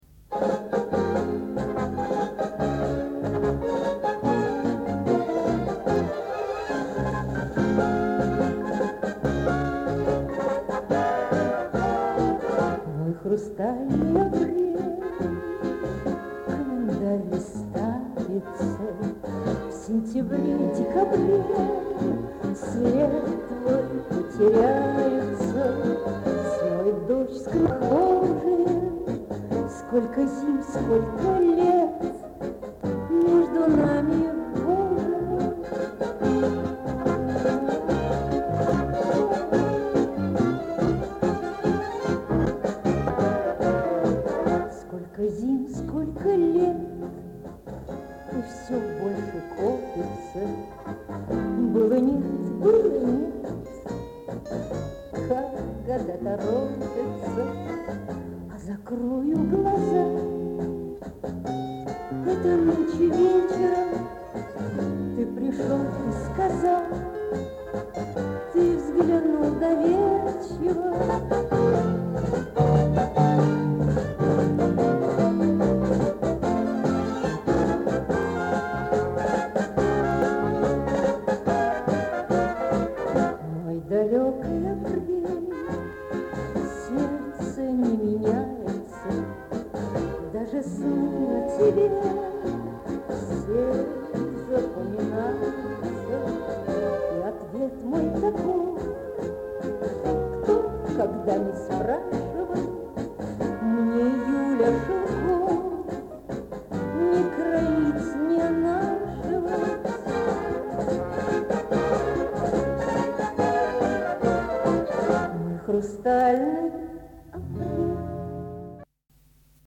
качество примерно года этак 60го.